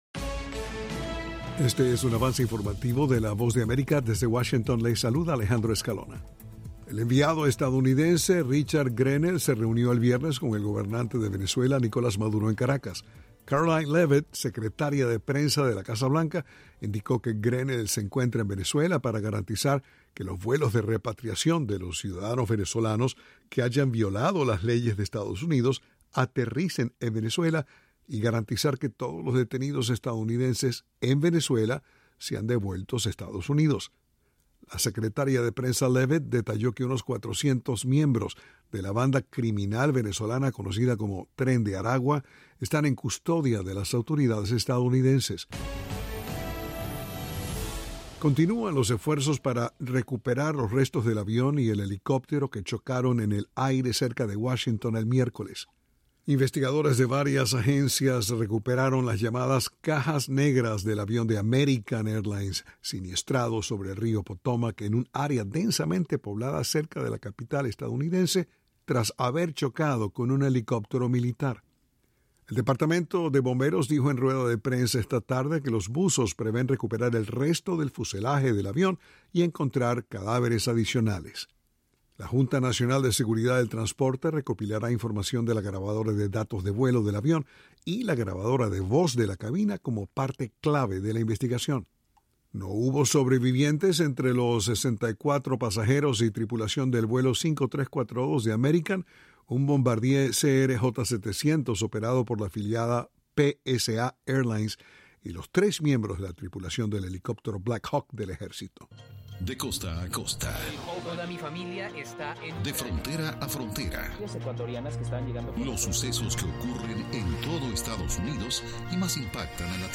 Avance Informativo
El siguiente es un avance informativo de la Voz de América.